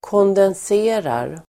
Uttal: [kåndens'e:rar]